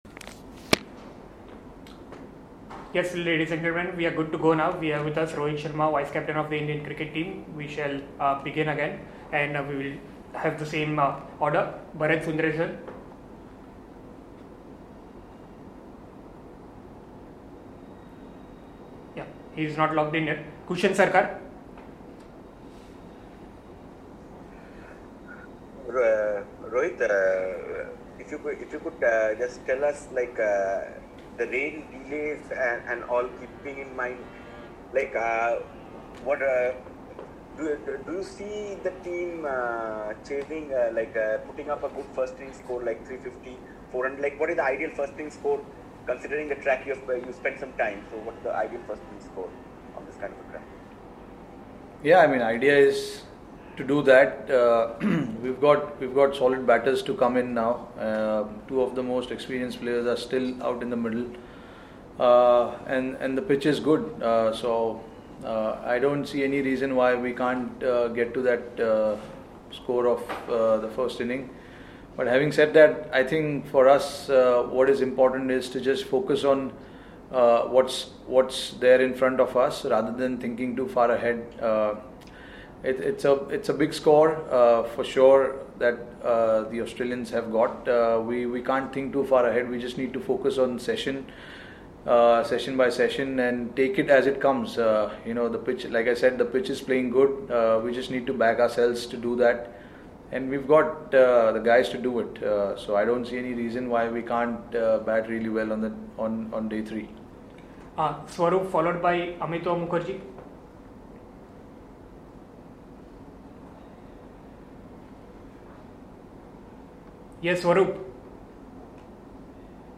Rohit Sharma, vice-captain, Indian Cricket Team addressed a virtual press conference after the second day’s play of the 4th Border-Gavaskar Test against Australia in Brisbane.